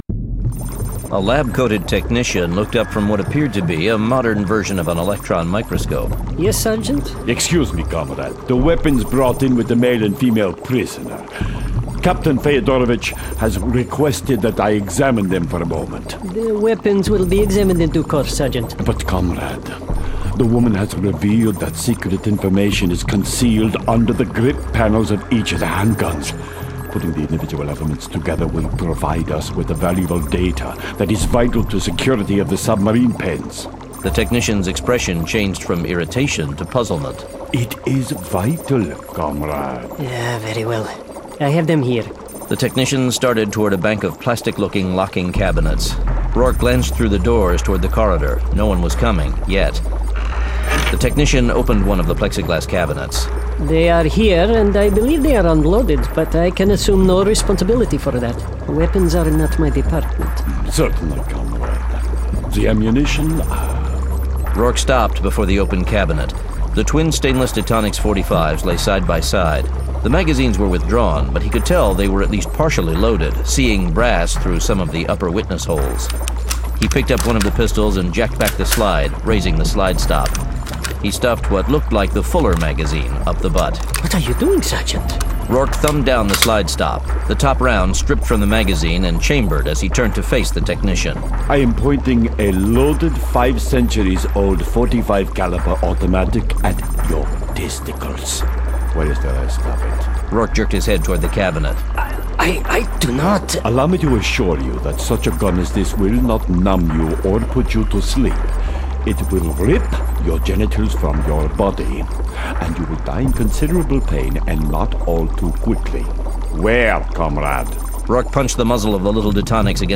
The Survivalist Audiobook Web Series -